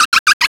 SEAL.WAV